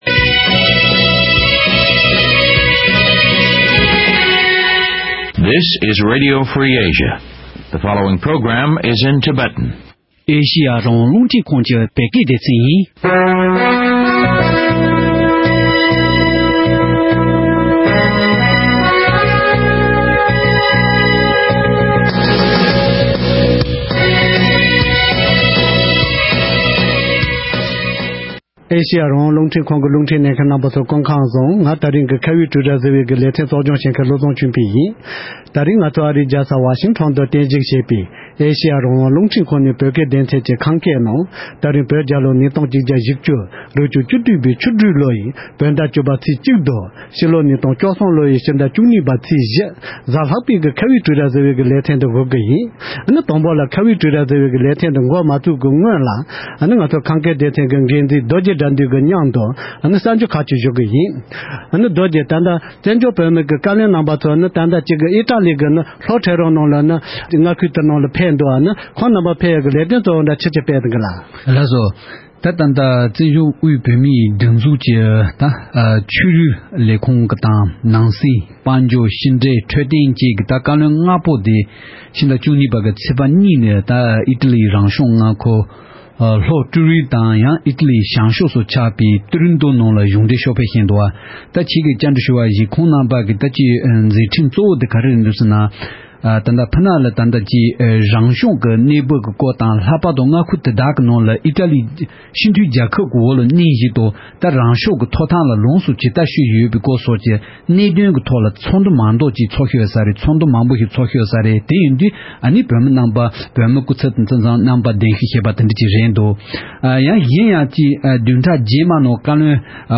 དབུ་མའི་ལམ་དང་རང་བཙན་གཉིས་ཀྱི་ལྟ་བའི་སྐོར་ལ་བགྲོ་གླིང་ཞུས་པའི་དམིགས་བསལ་ལེ་ཚན།